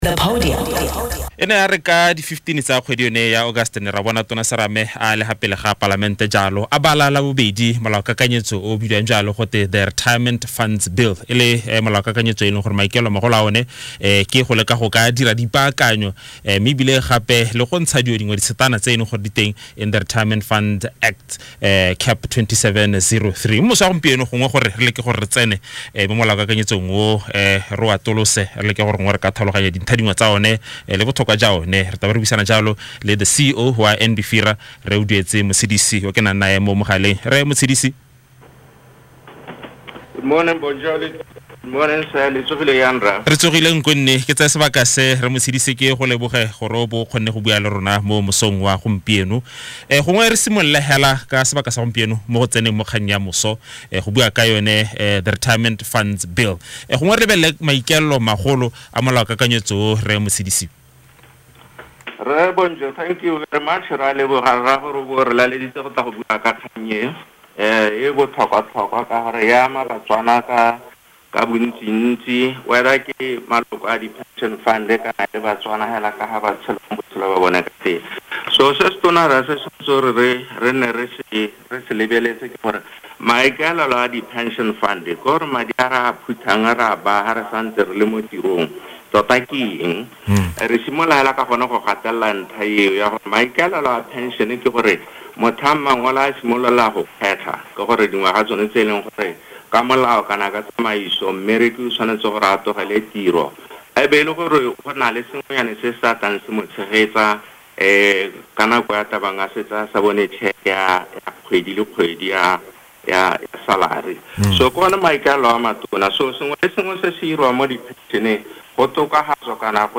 Today, Mr. Oduetse A. Motshidisi, Chief Executive Officer of the Non-Bank Financial Institutions Regulatory Authority (NBFIRA) conducted an interview on DumaFM, where he provided clarity on aspects of the proposed amendments that have drawn the most public attention to date.